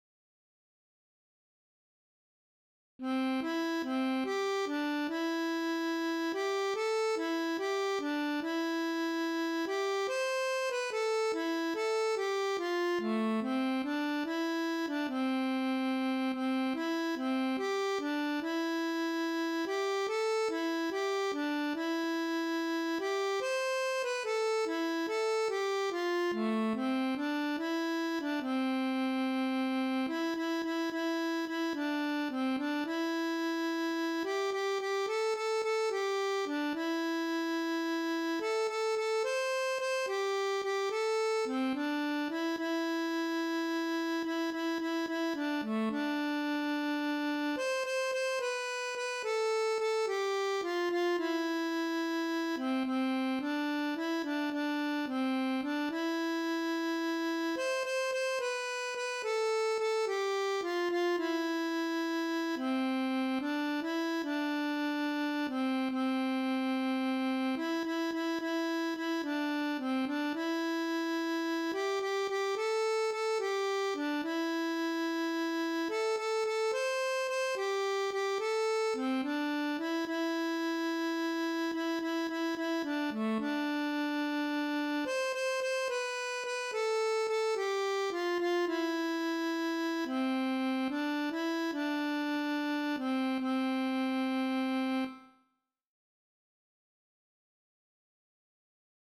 Finale Harmonica playout